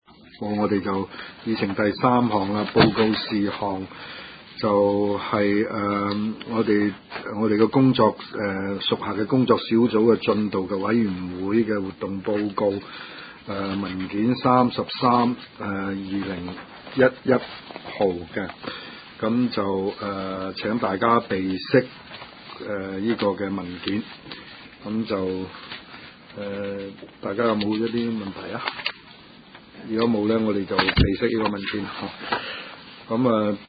灣仔民政事務處區議會會議室